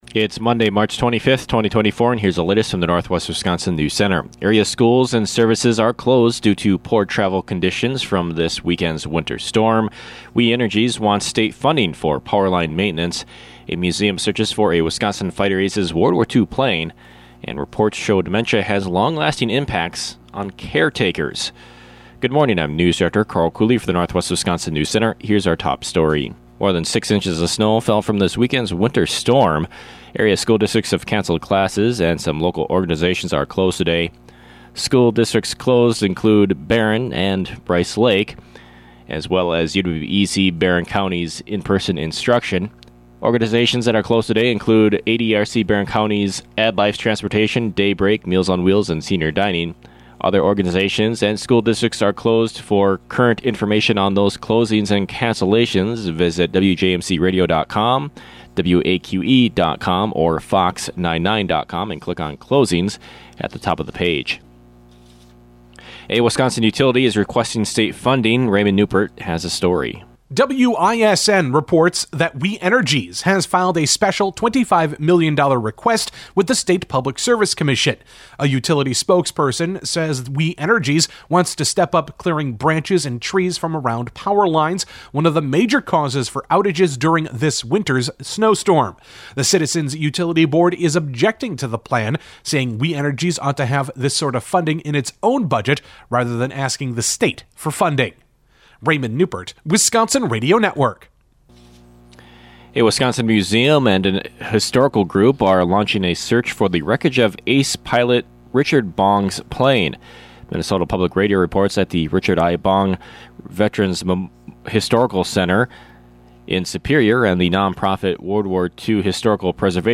AM NEWSCAST – Monday, March 25, 2024 | Northwest Builders, Inc.